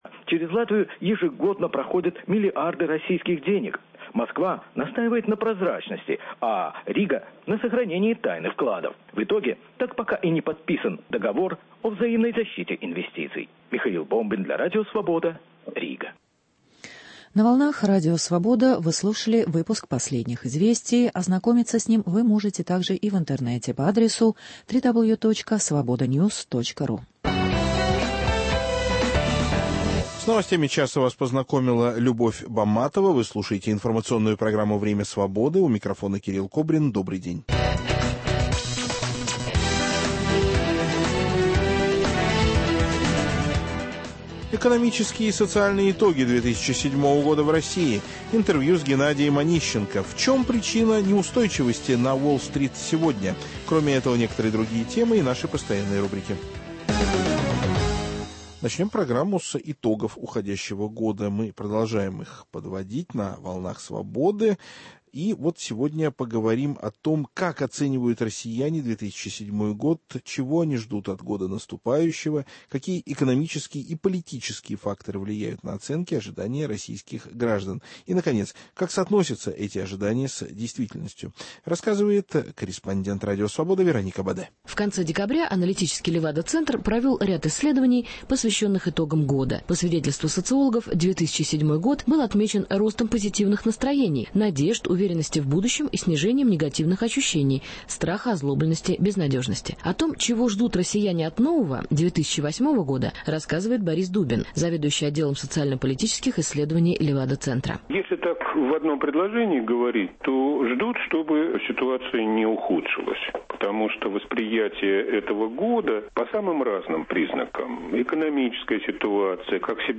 Экономические и социальные итоги 2007-го года в России. Международные отклики на убийство Беназир Бхутто. Интервью с Геннадием Онищенко.